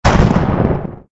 lightning_2.ogg